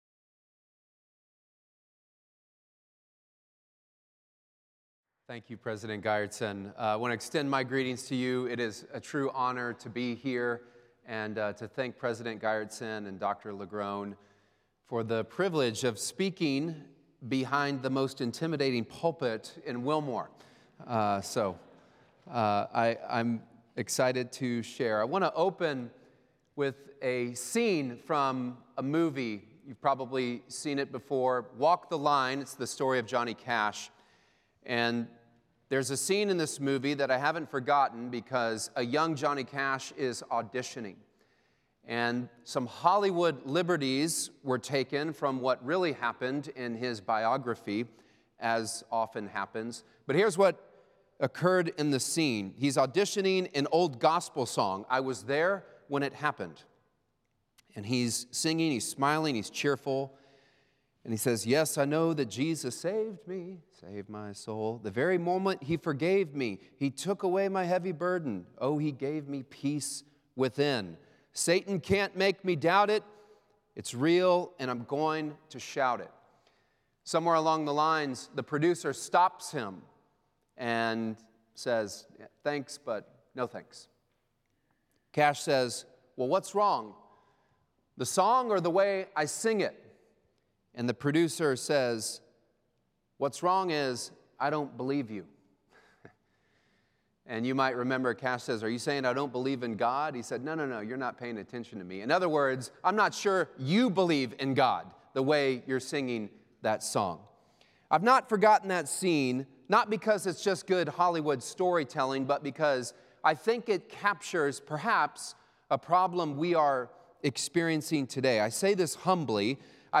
The following service took place on Tuesday, March 11, 2025.